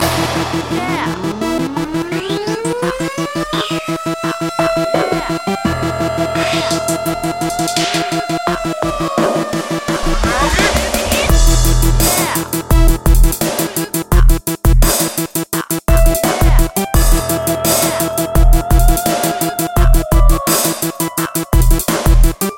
标签： 85 bpm Rap Loops Groove Loops 3.80 MB wav Key : Unknown
声道立体声